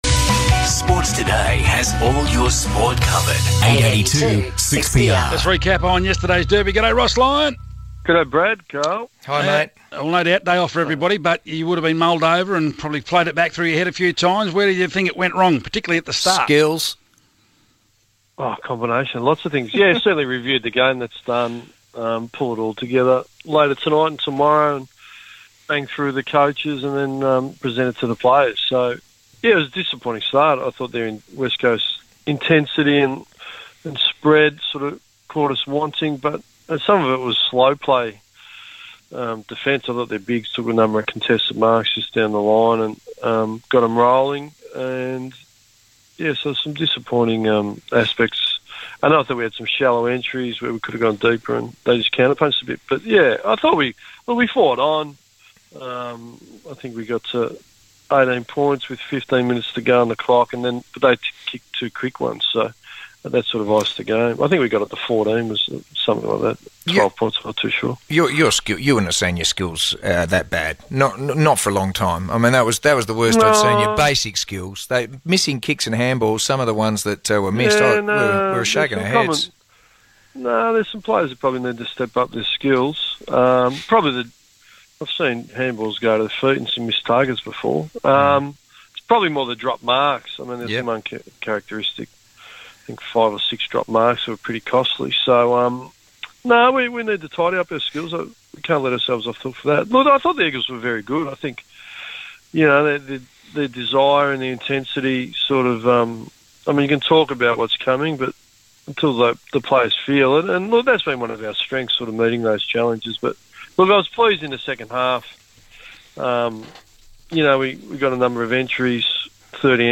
Ross Lyon spoke to Sports Today following the loss over West Coast on Sunday